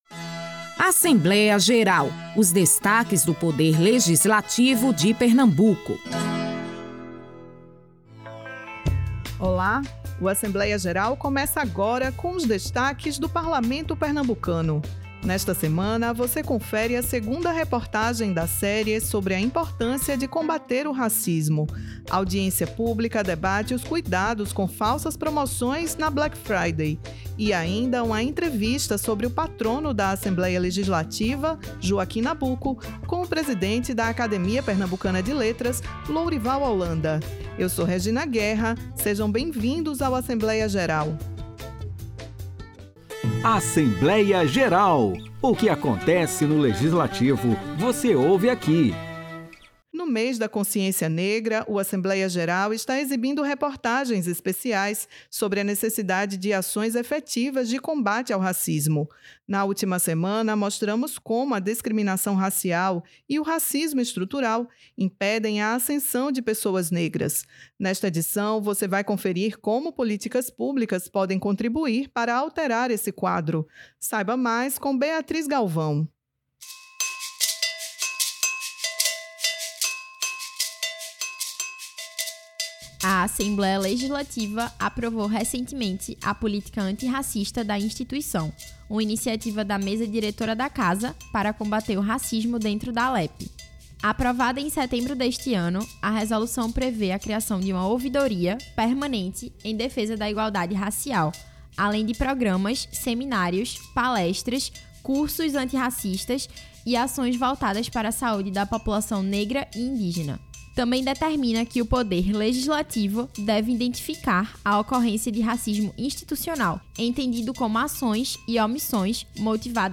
A edição desta semana do Assembleia Geral destaca o Mês da Consciência Negra com a segunda reportagem da série sobre o combate ao racismo. Neste episódio, as discussões mostram como políticas públicas podem ser instrumentos poderosos para enfrentar o racismo estrutural e garantir mais equidade.
O Assembleia Geral é uma produção semanal da Rádio Alepe, com os destaques do Legislativo pernambucano.